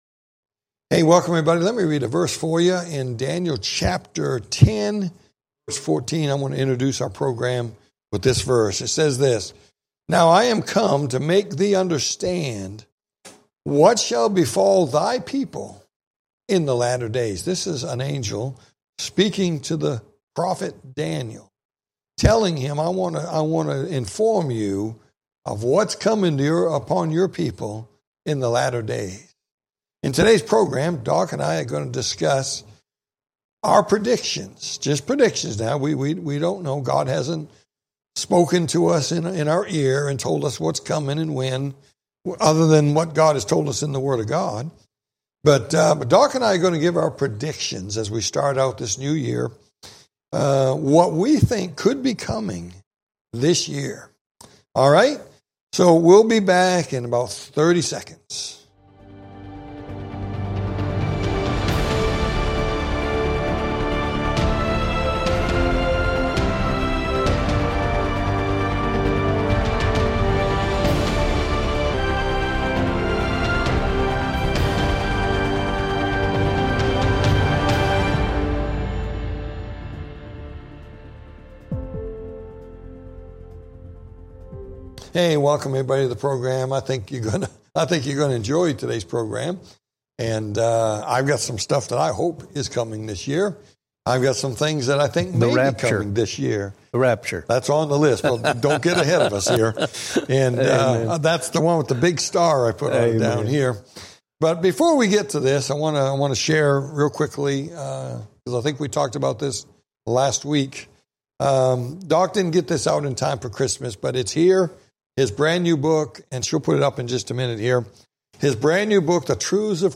Talk Show Episode, Audio Podcast, Prophecy In The Spotlight and Predictions For 2026 on , show guests , about Predictions For 2026, categorized as History,News,Politics & Government,Religion,Society and Culture,Theory & Conspiracy